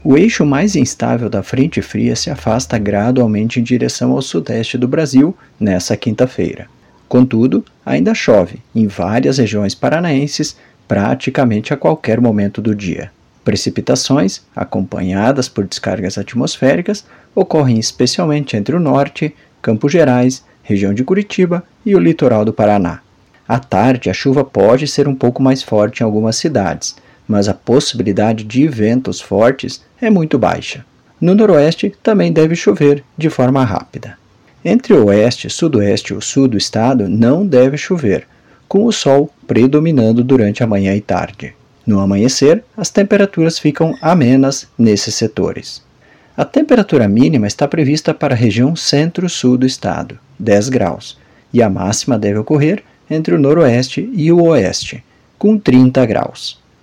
Previsão do tempo